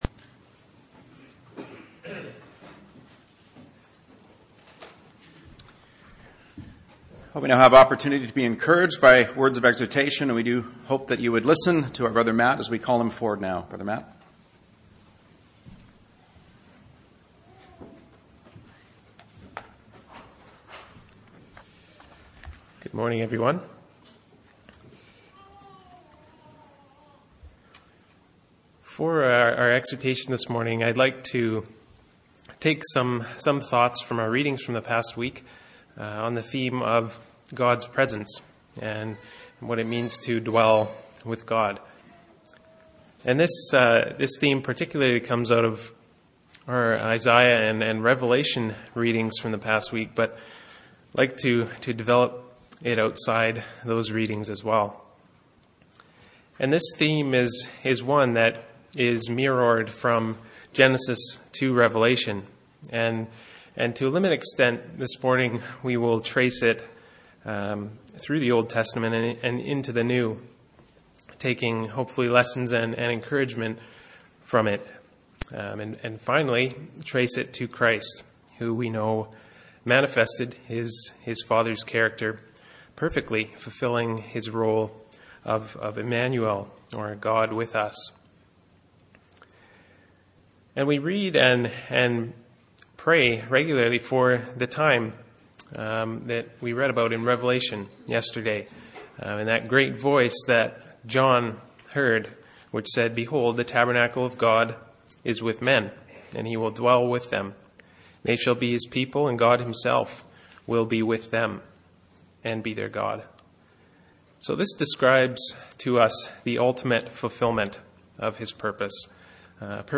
Exhortation 07-02-17